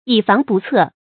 以防不测 yǐ fáng bù cè
以防不测发音
成语正音不；必须读作“bú”。